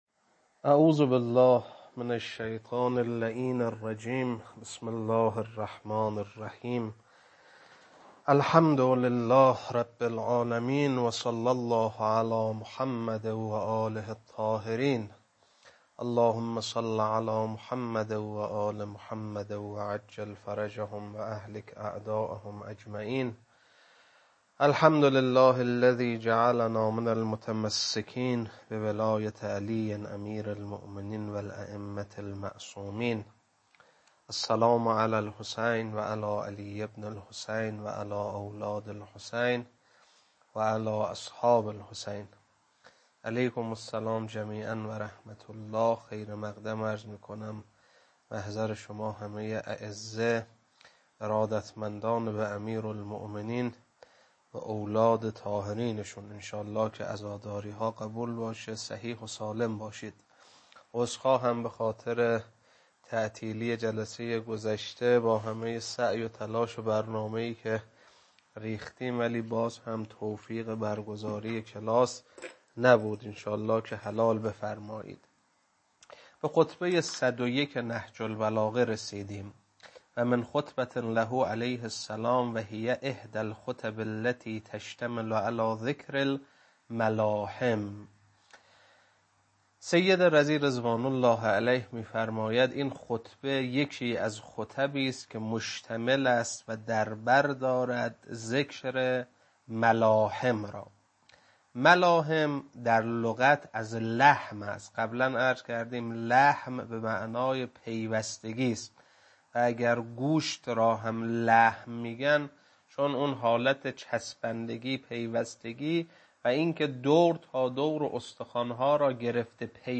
خطبه 101.mp3